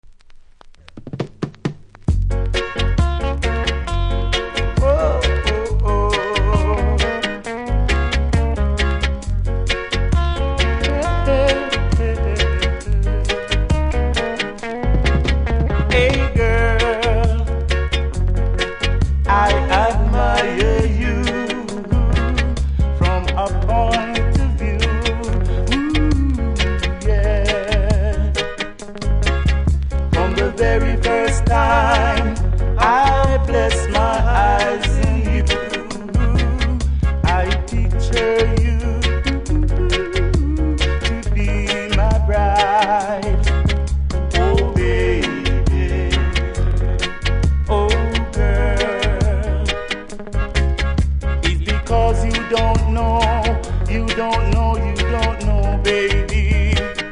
REGGAE 70'S
素晴らしいラブソング♪でカバーも出ております。